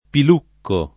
piluccare v.; pilucco [ pil 2 kko ], ‑chi